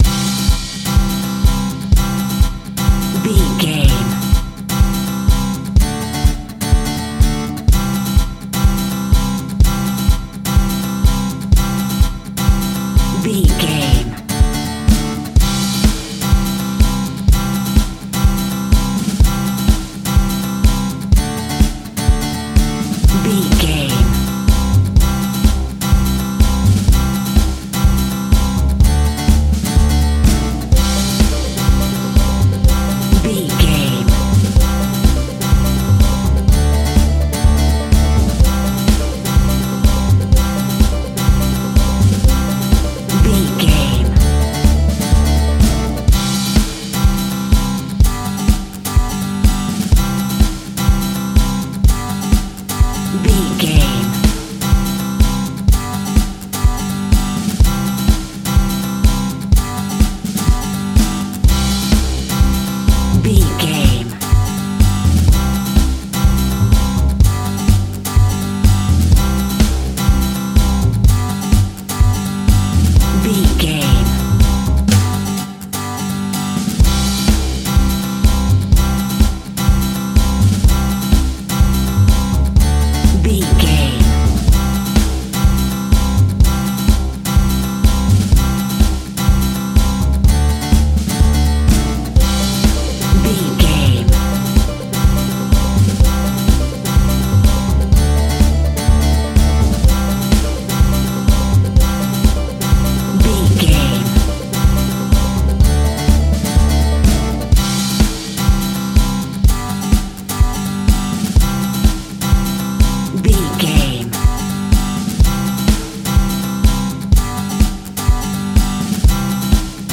Ionian/Major
indie pop
indie rock
pop rock
sunshine pop music
drums
bass guitar
electric guitar
piano
hammond organ